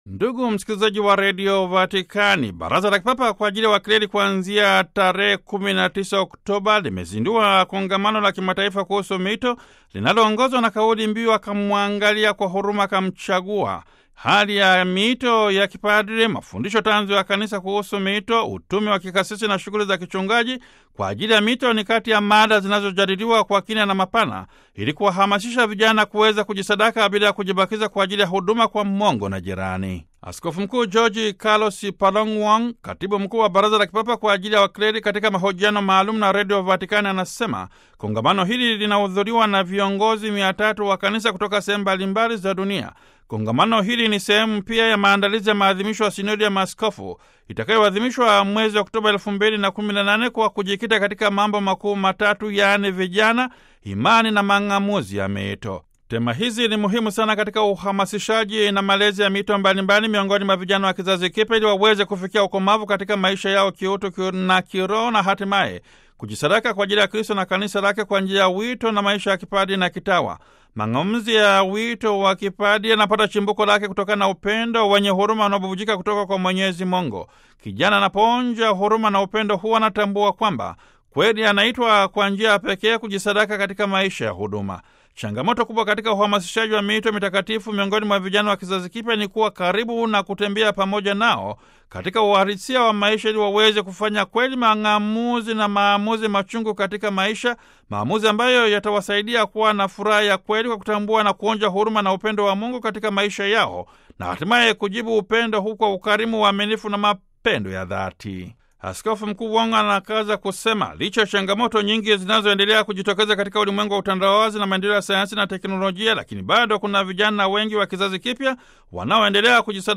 Askofu mkuu Jorge Carlos Paròn Wong, Katibu mkuu wa Baraza la Kipapa kwa ajili ya Wakleri katika mahojiano maalum na Radio Vatican anasema, kongamano hili linahudhuriwa na viongozi 300 wa Kanisa kutoka sehemu mbali mbali za dunia.